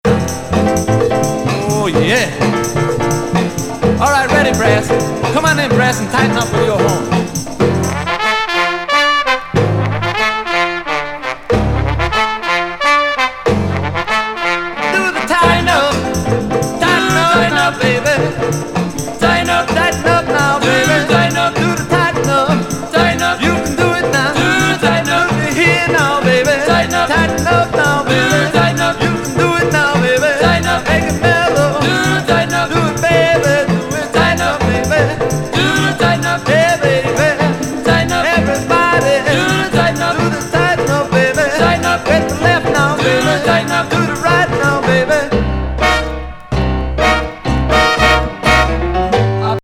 濃厚BOOGALOO68年作!これまた問答無用にFUNKY